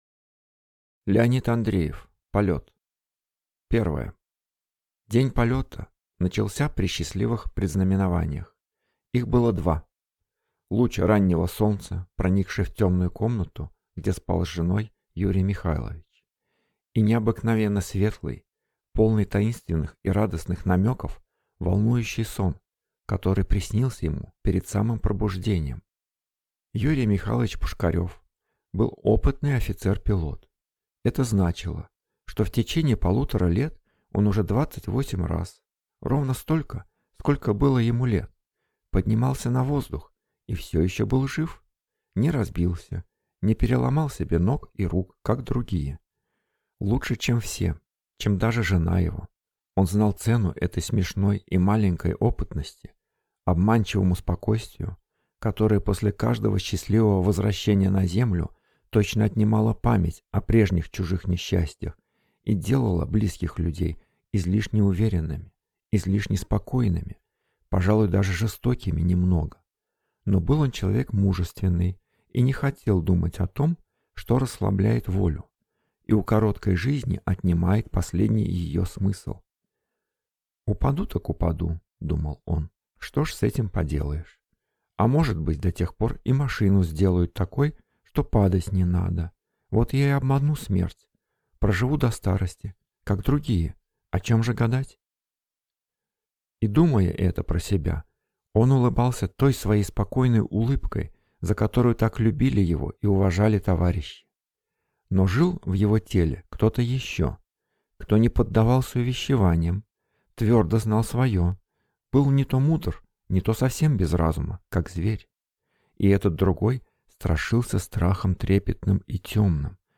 Аудиокнига Полёт | Библиотека аудиокниг